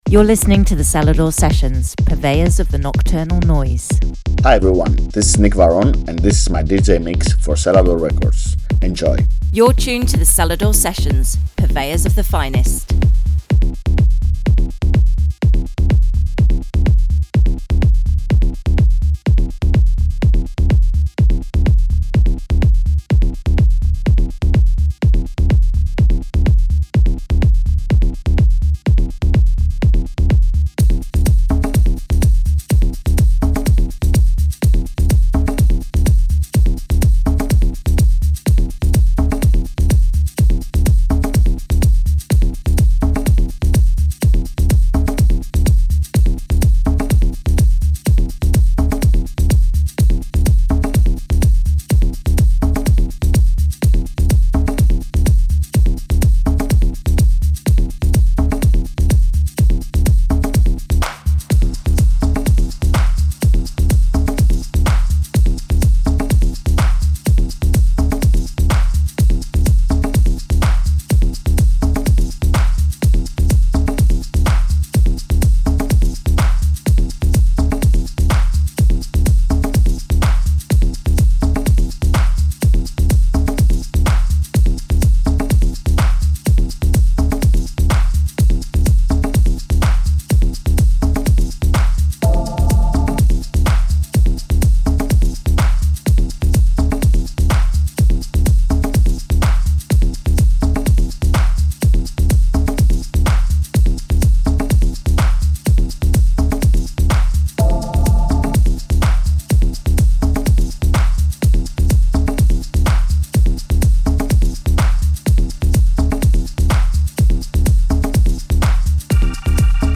the Argentinean DJ and producer
for an hour of damn tasty tunes!